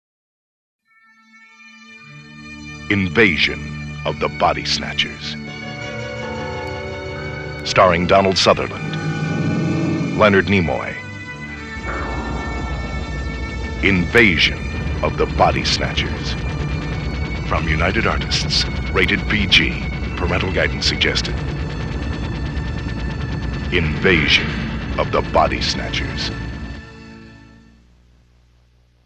Radio Spots
The stereo spots are basically the same with minor differences, but the mixing is a little sharper and the background effects are more pronounced.